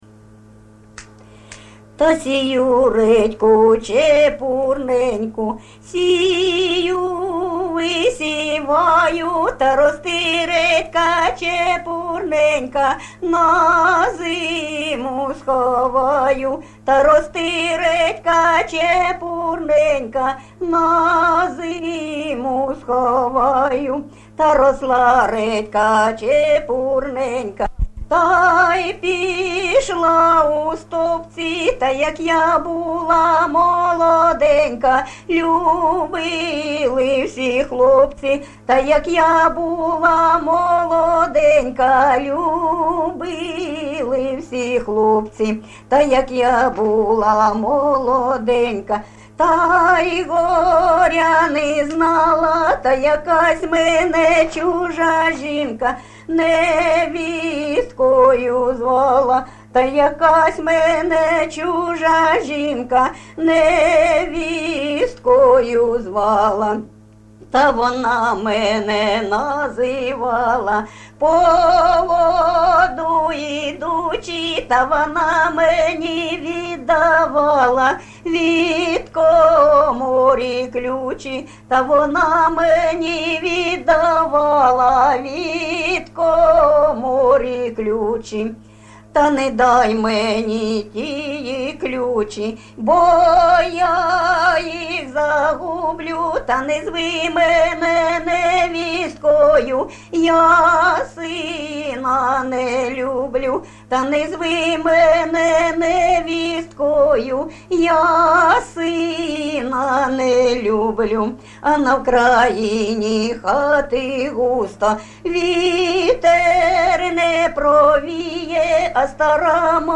ЖанрПісні з особистого та родинного життя
МотивРодинне життя, Журба, туга